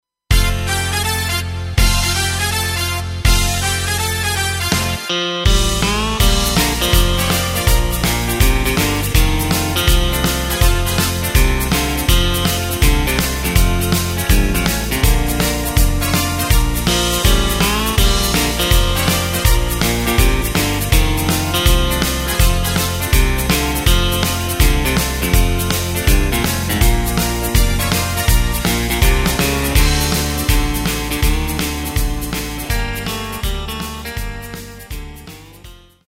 Takt:          4/4
Tempo:         163.00
Tonart:            Bb
Schlager Instrumental -GUITAR!